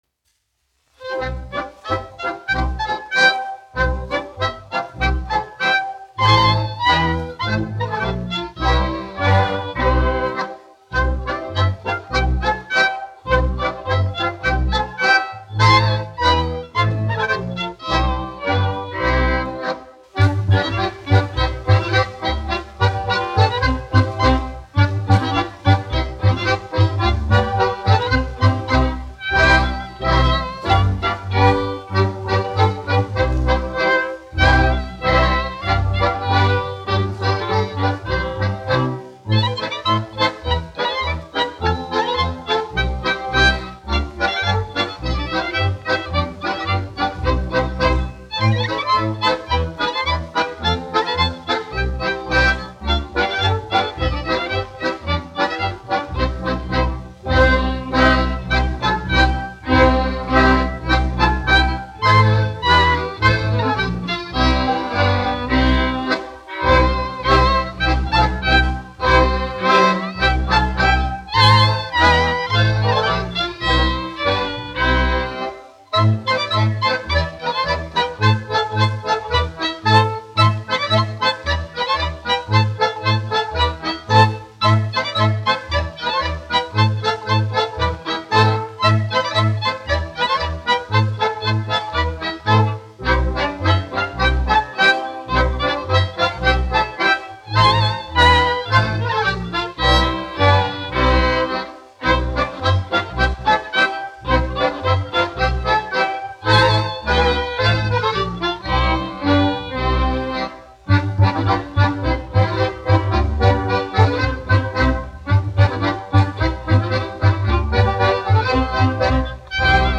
Kamoliņa deja : tautas deja
Alfrēda Vintera Jautrā kapela (mūzikas grupa), izpildītājs
1 skpl. : analogs, 78 apgr/min, mono ; 25 cm
Latviešu tautas dejas
Latvijas vēsturiskie šellaka skaņuplašu ieraksti (Kolekcija)